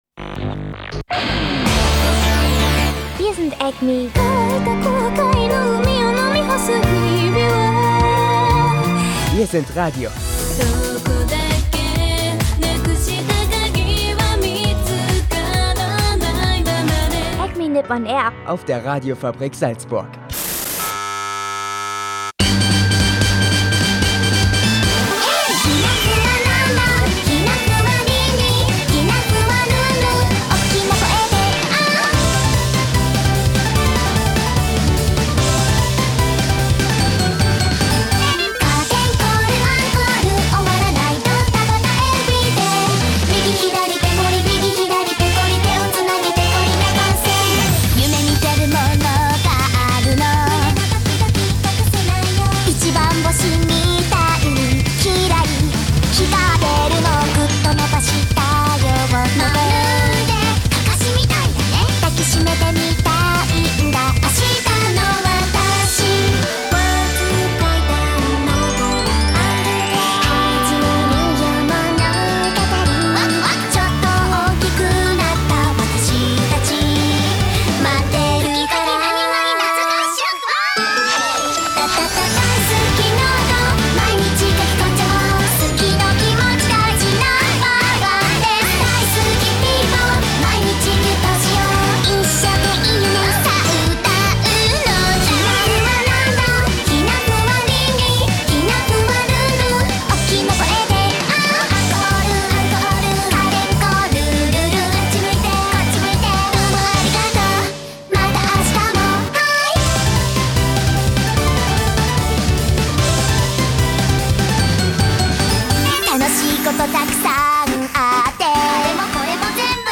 In einem total verhitzten Studio geht es heute um den Anime Hinato Note, der von einer jungen schüchternen Landpromeranze handelt, die in Tokyo versucht ihre Schüchternheit loszuwerden.
Dazu wie immer frische Anime.Musik passend zum Wetter.